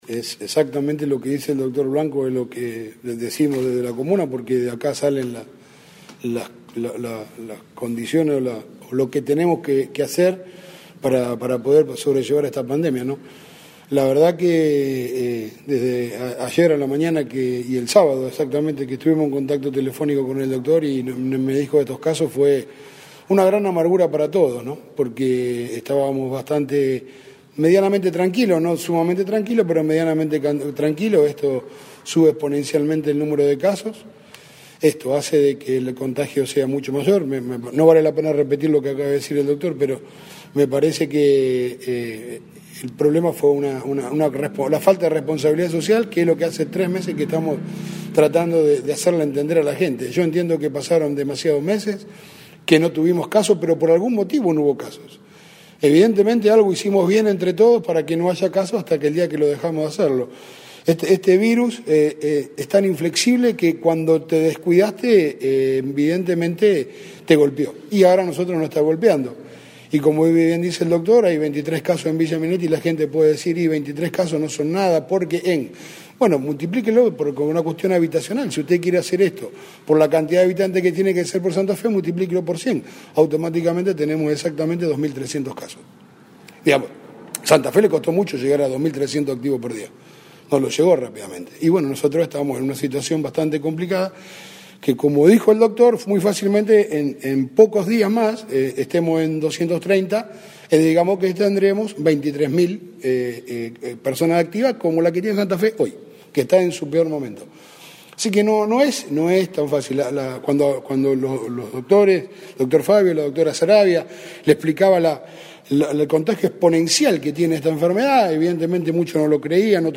La palabra del presidente comunal de Villa Minetti: